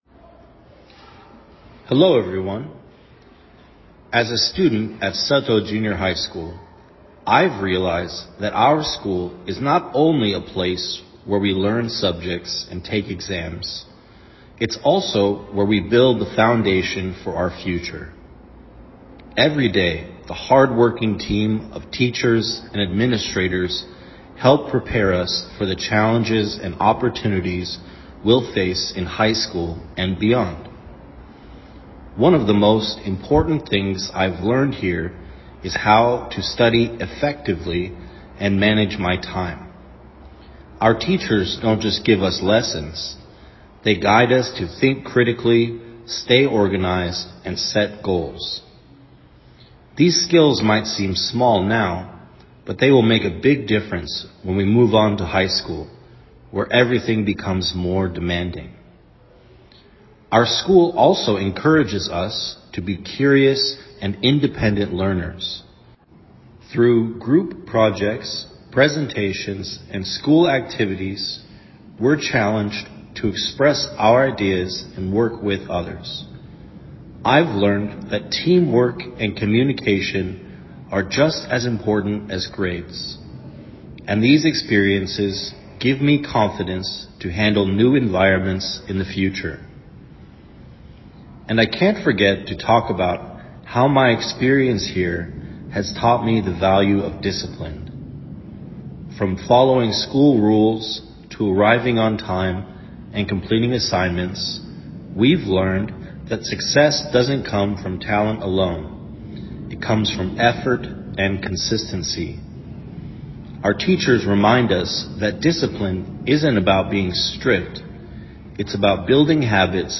英語演說音檔.mp3